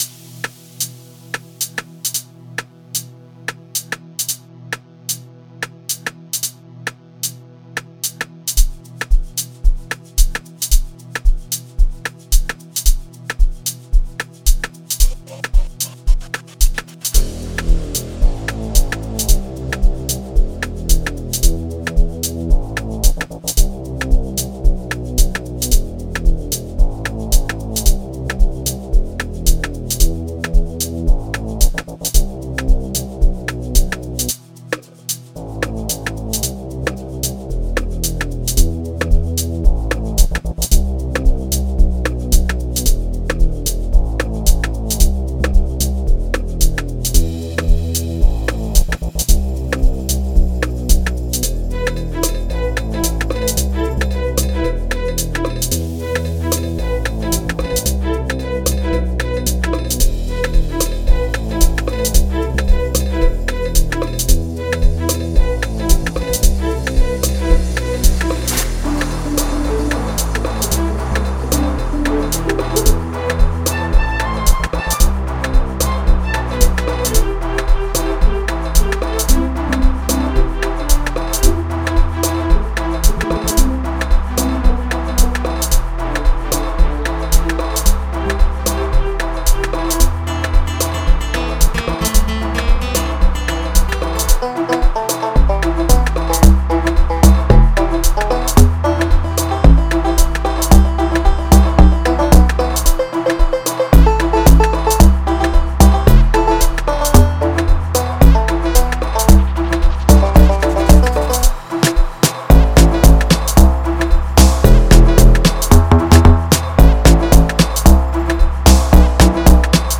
06:23 Genre : Amapiano Size